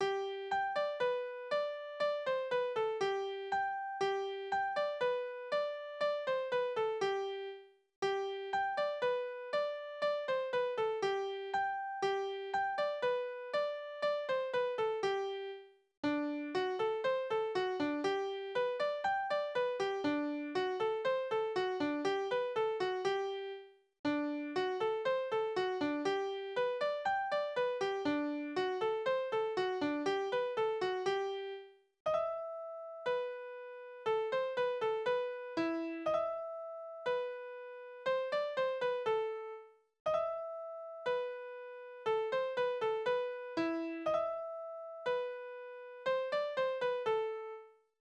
Koseky Tanzverse: Tonart: G-Dur Taktart: 2/4
Besetzung: instrumental
Vortragsbezeichnung: schnelles Polkazeitmaß